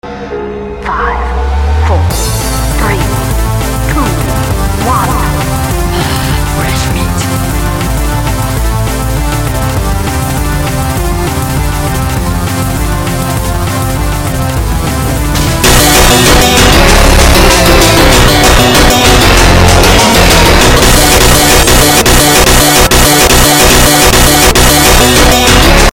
Peak hit sound (IT WAS SO LOUD)